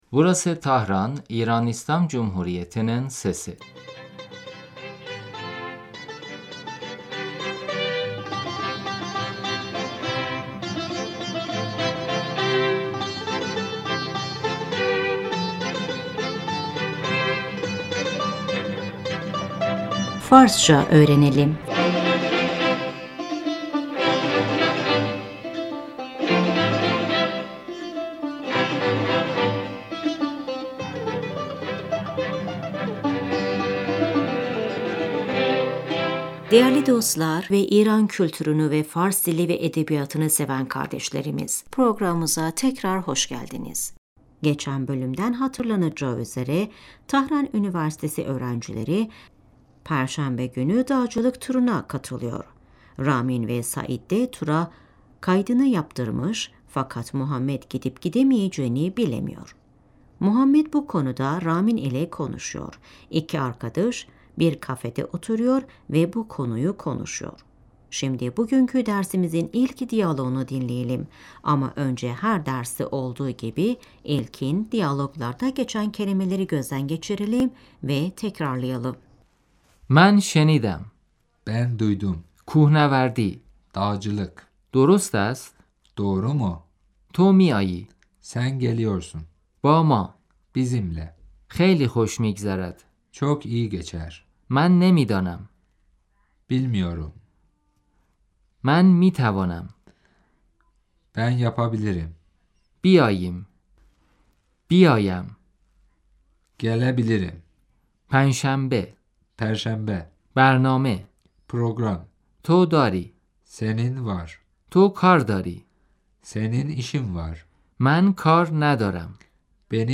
Şimdi iki arkadaş arasında geçen diyaloğu dinleyin ve tekrarlayın. صدای گفتگوی افراد - در کافی شاپ Kafede insanların konuşma sesi محمد - شنیدم تو و سعید به کوهنوردی می روید .